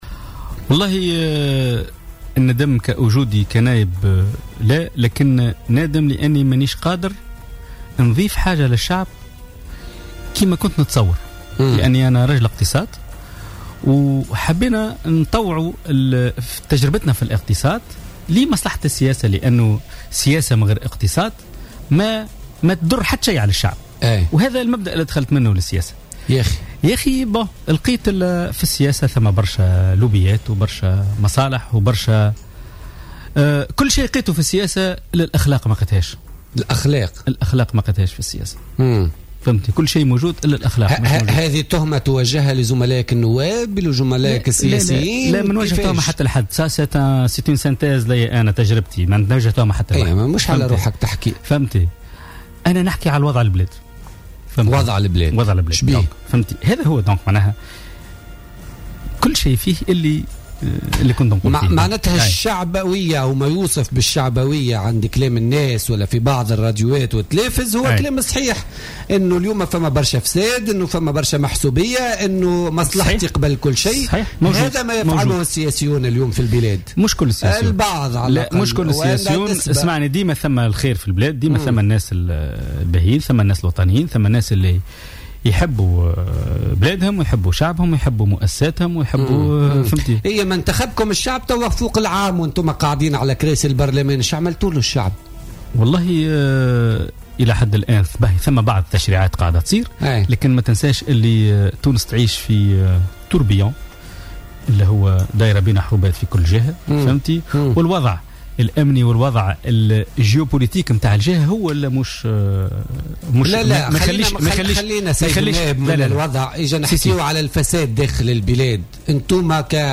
قال النائب عن حزب آفاق تونس ورجل الأعمال، حافظ الزواري، ضيف برنامج "بوليتيكا" اليوم الثلاثاء إنه لم يندم يوما على وجوده كنائب في مجلس الشعب، لكنه مستاء من كونه لم يستطع أن يقدّم إضافة في المجال الاقتصادي كما كان يتصوّر.